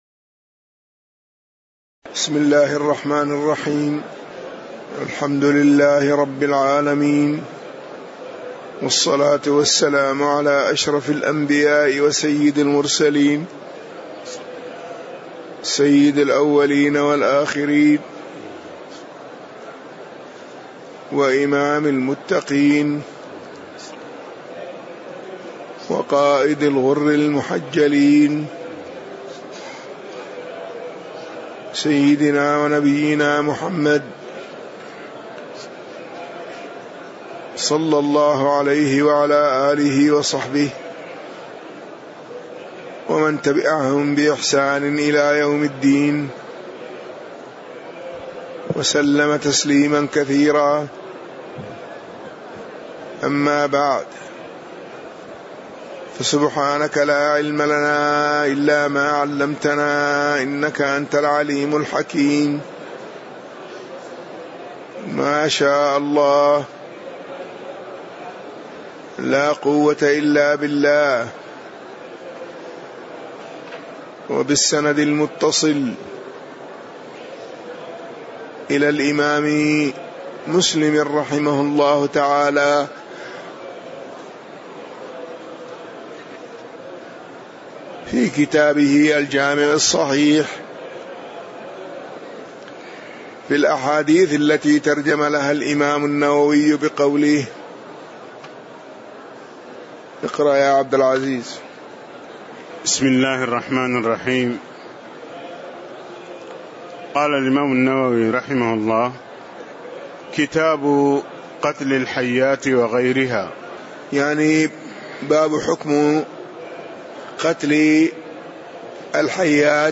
تاريخ النشر ٢٤ ربيع الأول ١٤٣٧ هـ المكان: المسجد النبوي الشيخ